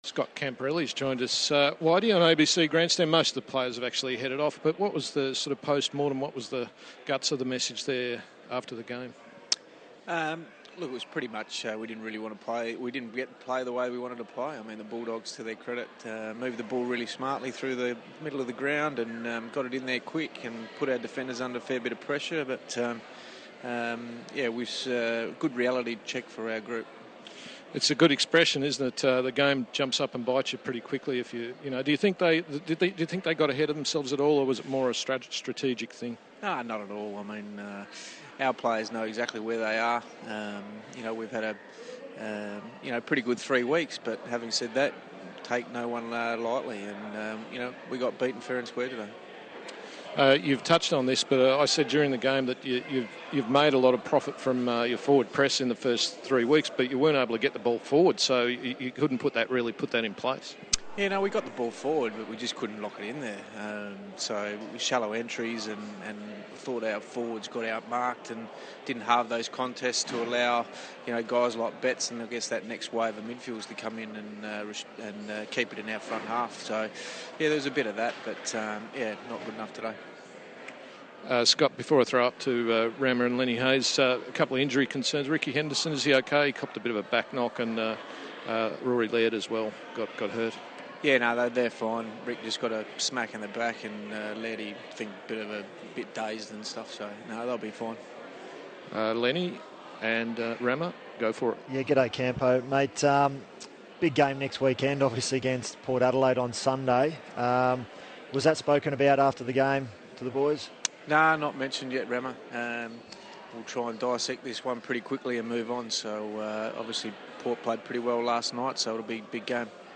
Scott Camporeale on SEN